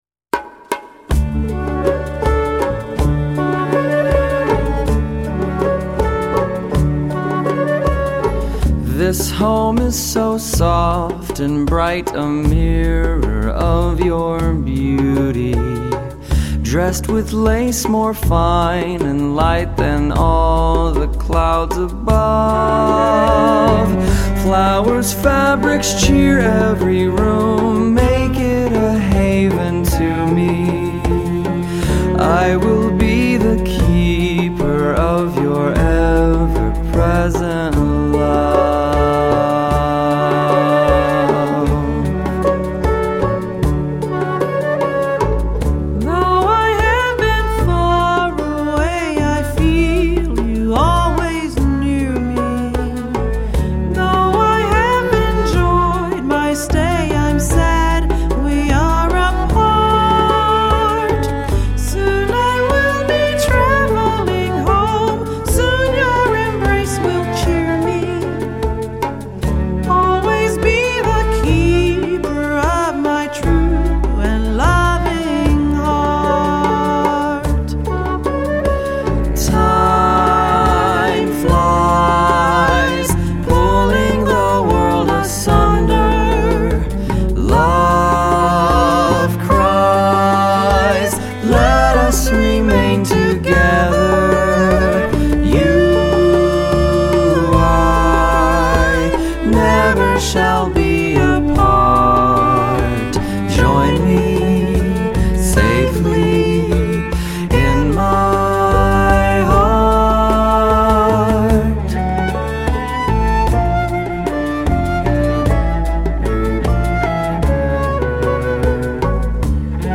blend Blues Grass and Jazz idioms